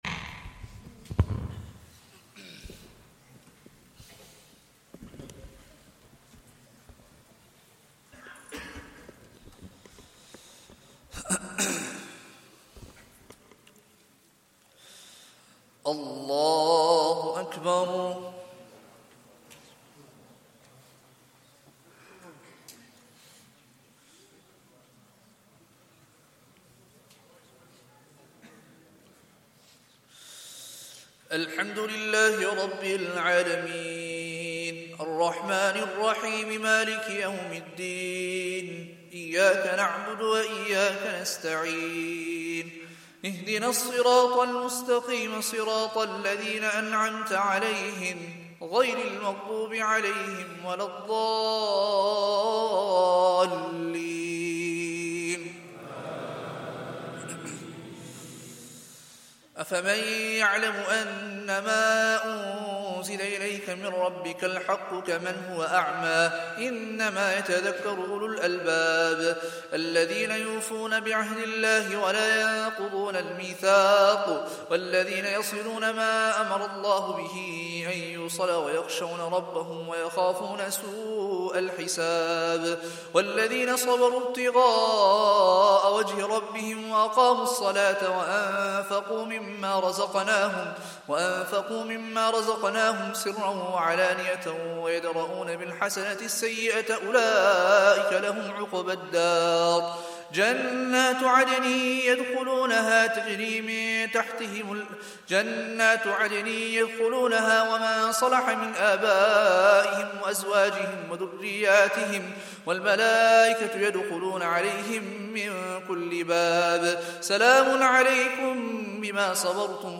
Day 11 - Taraweeh Recital - 1445